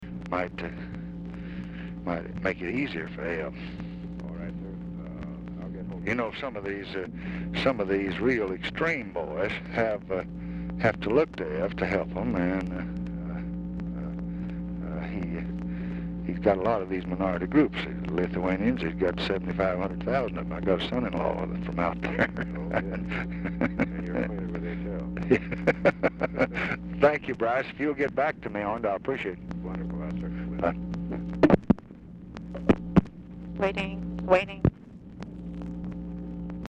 Telephone conversation # 11504, sound recording, LBJ and BRYCE HARLOW, 2/2/1967, 10:37AM | Discover LBJ
Format Dictation belt
Location Of Speaker 1 Oval Office or unknown location